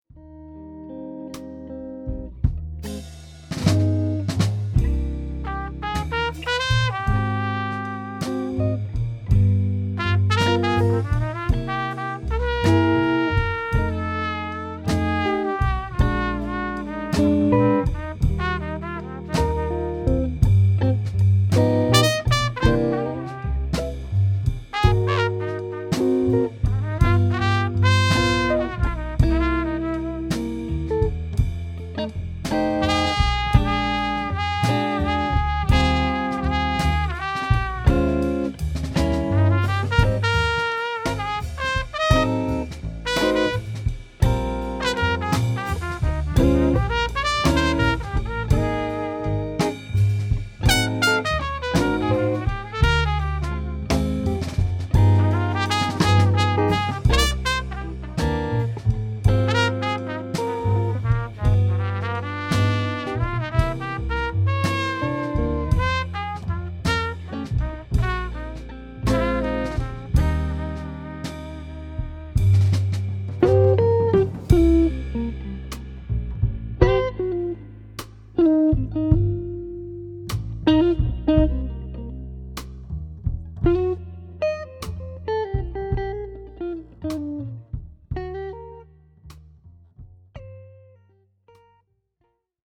(en quartet : trompette, guitare, contrebasse, batterie) :
Blues -